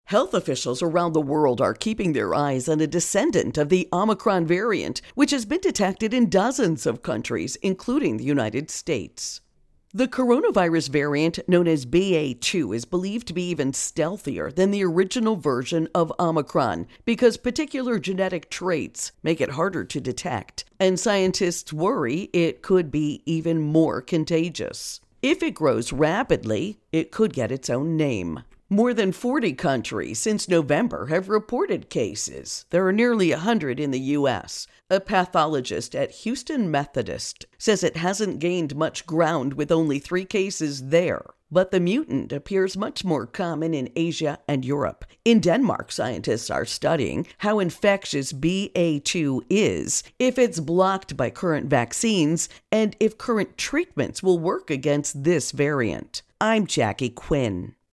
MED Virus Outbreak Omicron Variant Explainer Intro and Voicer